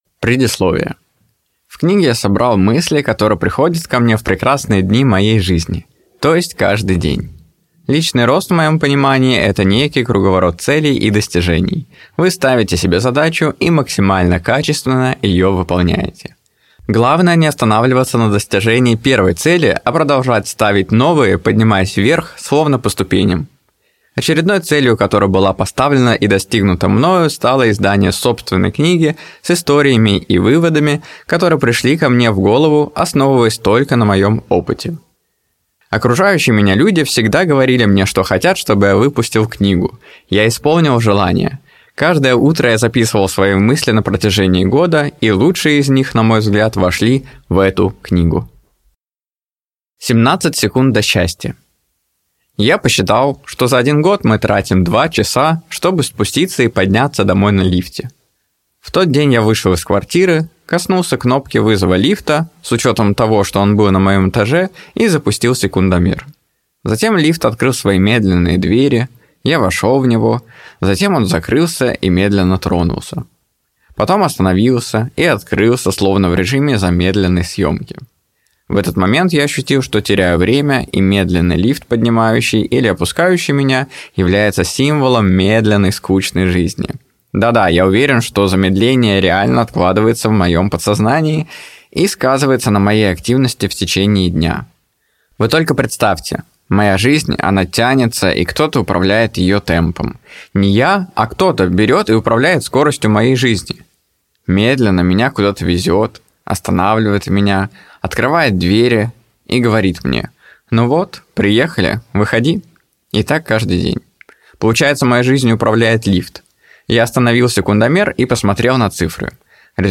Аудиокнига Идеал | Библиотека аудиокниг